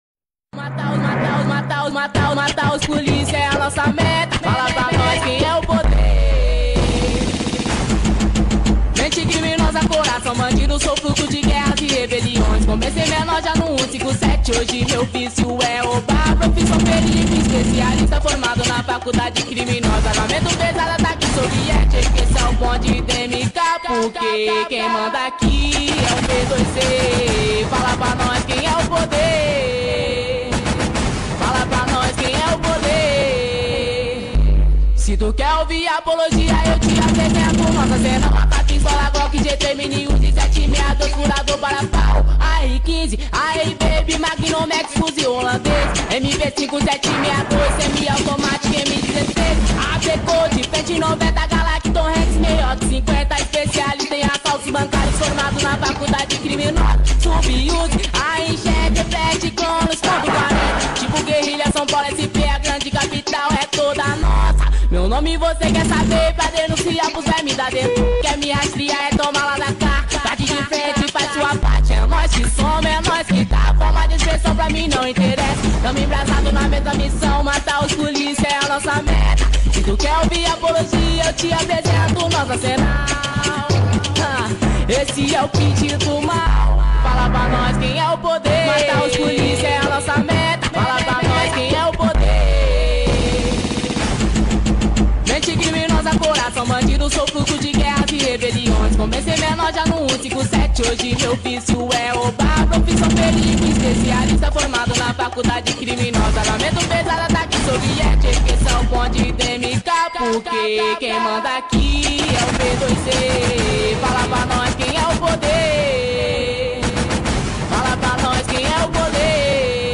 2025-03-06 21:37:55 Gênero: Funk Views